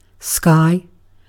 Ääntäminen
IPA : /skaɪ/ US : IPA : [skaɪ] UK